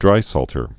(drīsôltər)